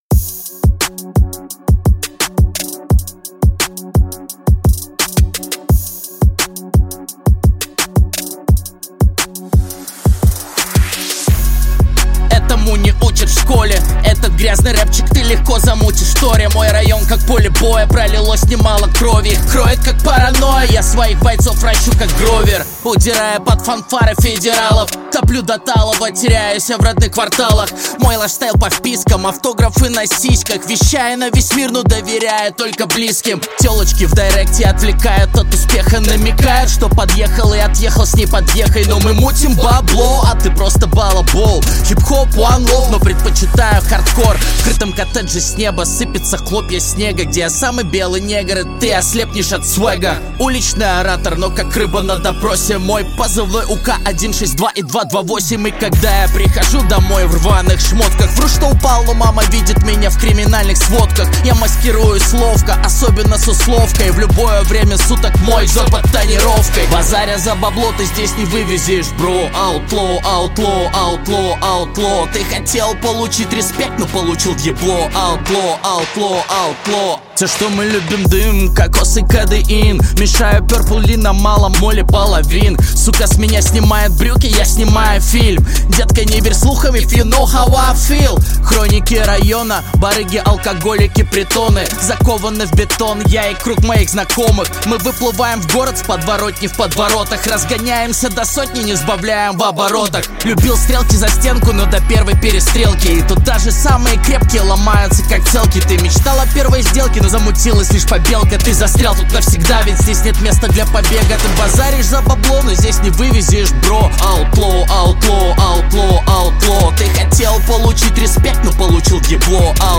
Жанр: Электроника / Саундтреки